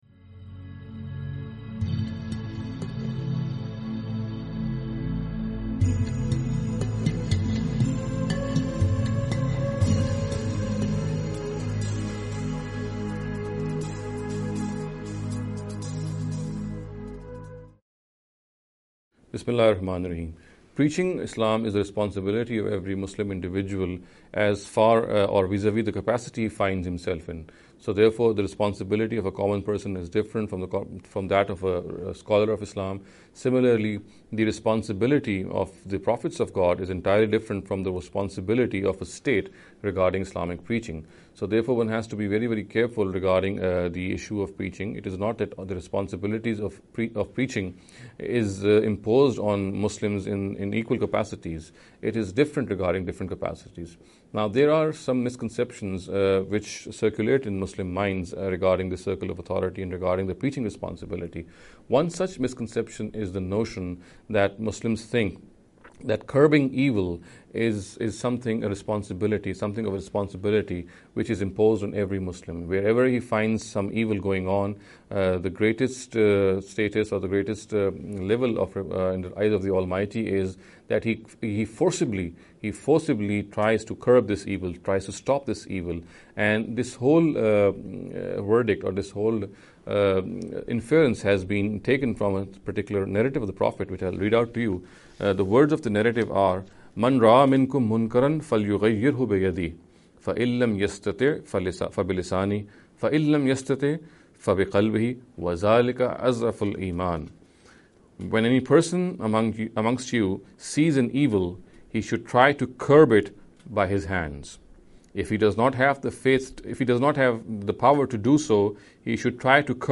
This lecture series will deal with some misconception regarding the Preaching Islam.